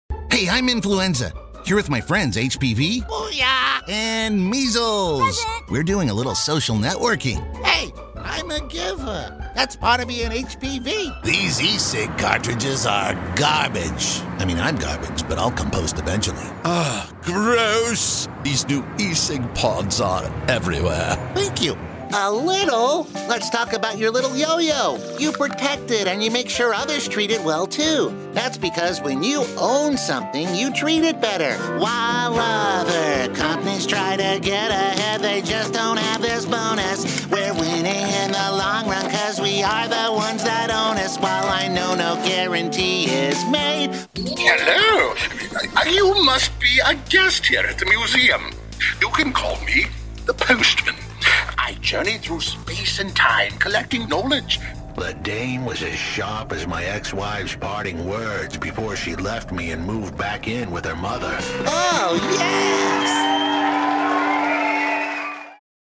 Young Adult, Adult
british rp | natural
standard us | natural
ANIMATION 🎬